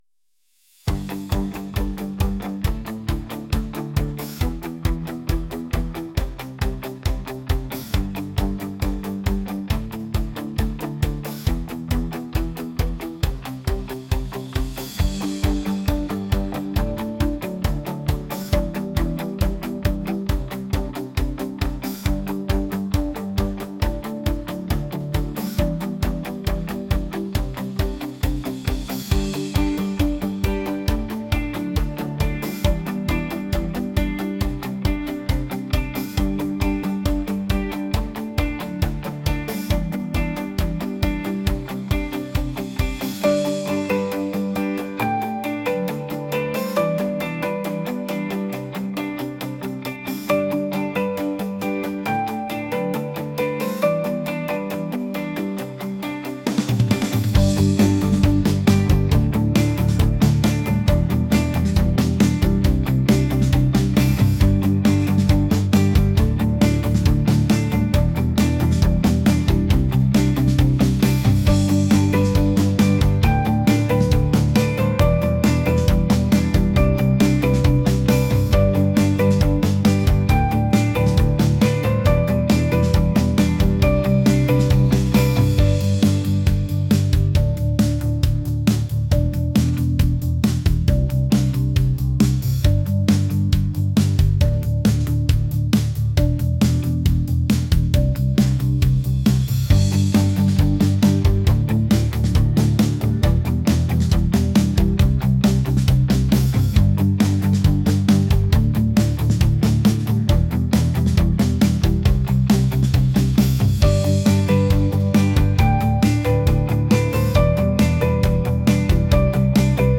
energetic | catchy | pop